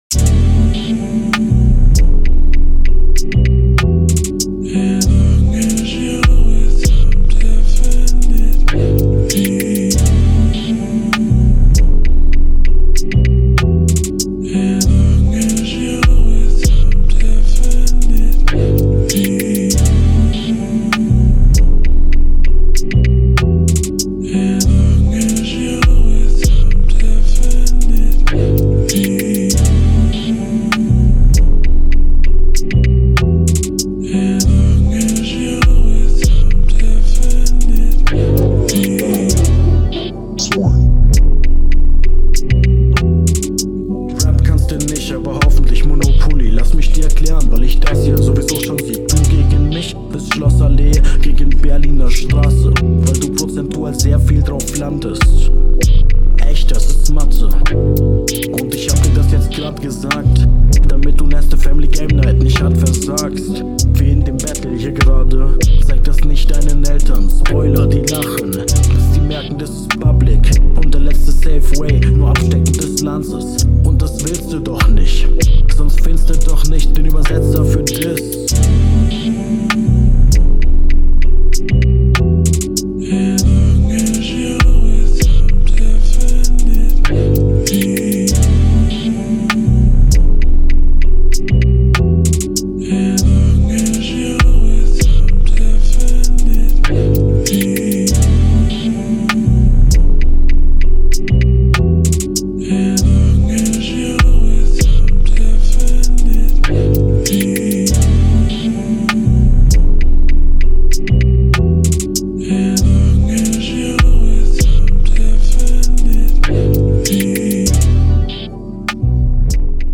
Intro und Outro sind viel,viel,viel zu lang.
Vocal könnte lauter sein und Beat läuft vieeeeel zu lang.